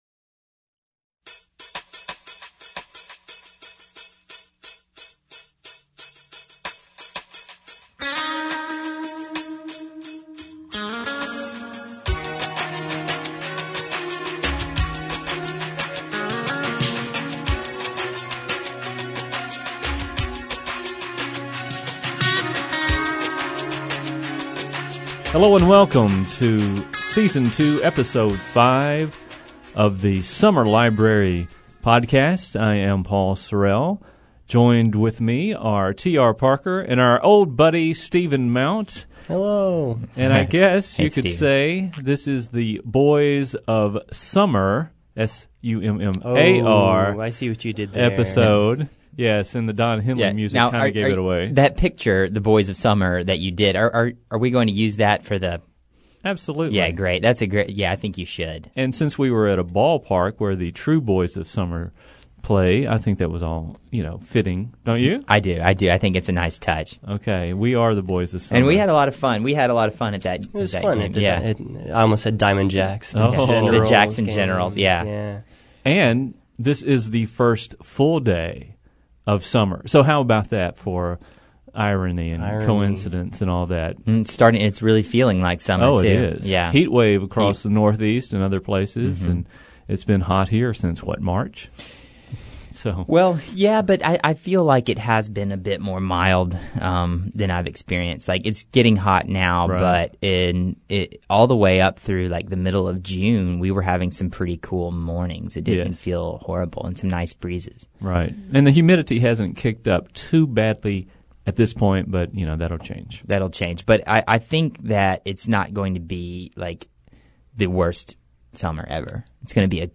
The conversation is casual yet informative.